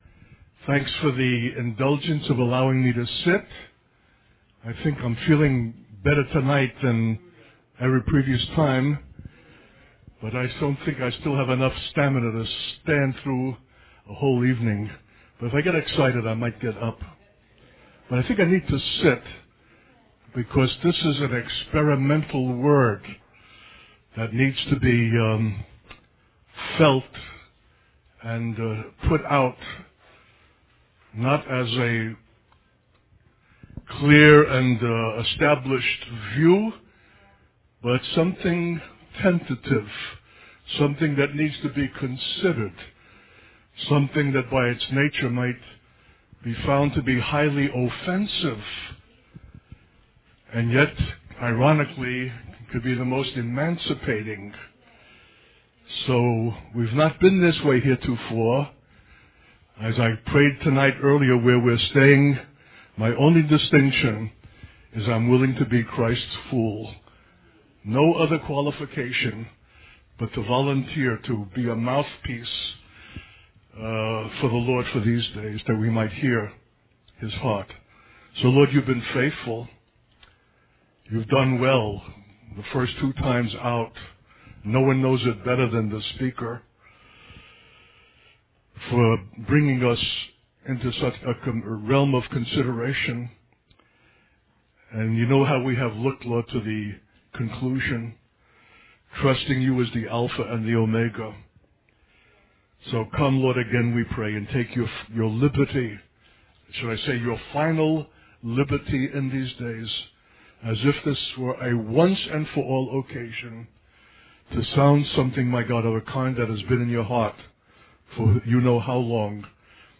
A message of an emancipating kind, particularly for the black church. A call to servanthood – the essence of what God is in Himself.